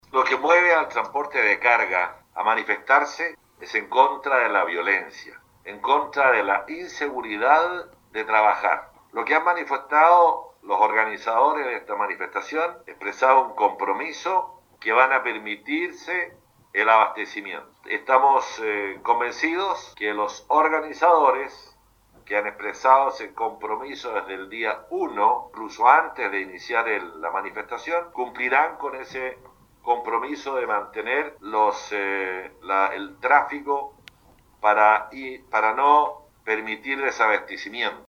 Así, el intendente señalaba que el abastecimiento de la zona está asegurado porque así lo habían declarado los dirigentes del trasporte.